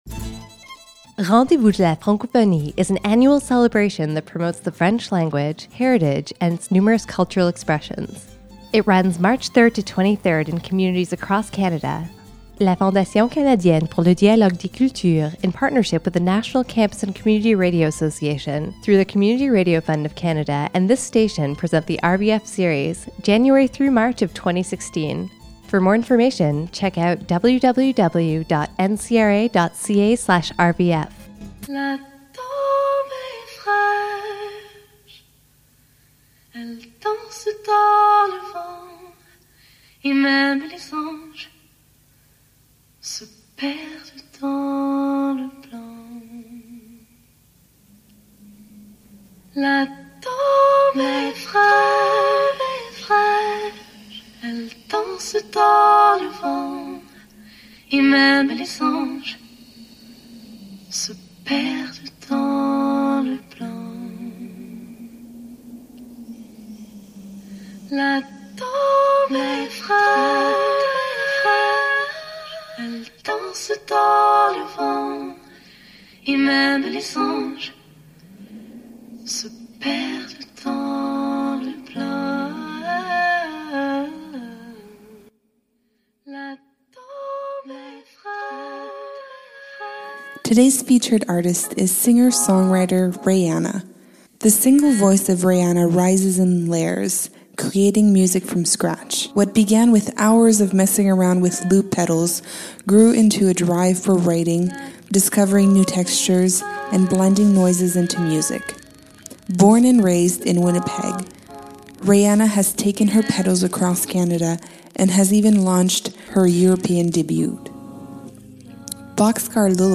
Capsules containing information, interviews, and music from different Western Canadian Francophone artists.